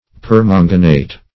Permanganate \Per*man"ga*nate\, n. (Chem.)